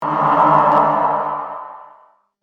Scary Sound Sfx Sound Button - Free Download & Play